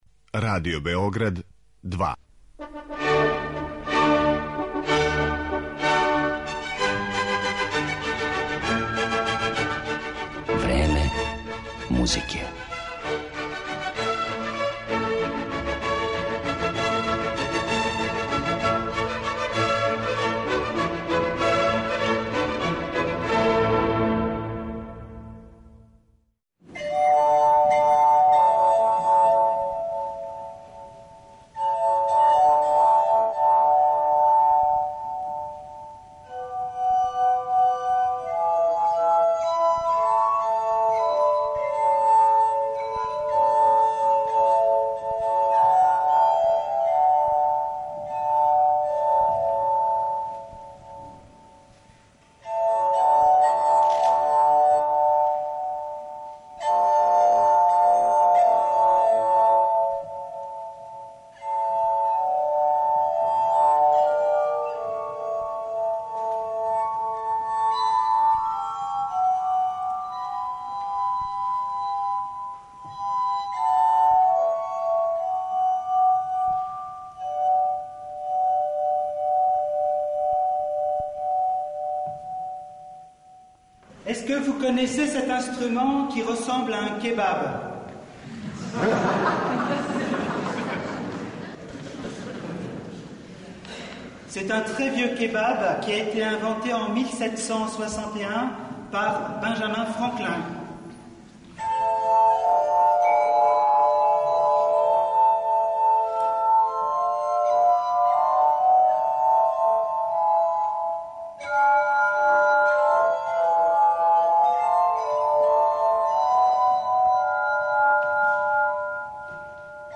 Слушаћете инструмент који је настао као плод инспирације свирања на чашама, гласхармонику и прави мушки сопран.